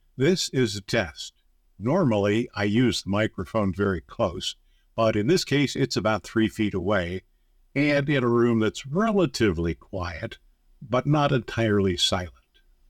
TechByter Worldwide is recorded in a room that was never intended to be a studio, but I place the microphone close and have some sound conditioning foam in place. I made a test recording with the microphone about 3 feet away and this is the result:
Then I used the application’s Enhance Speech filter:
The noise is gone, but the hard limiter seems to be a bit too aggressive.
As a result, room noise and echo were apparent, although still within the acceptable range.